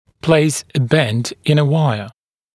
[pleɪs ə bend ɪn ə ‘waɪə][плэйс э бэнд ин э ‘уайэ]сформировать изгиб на дуге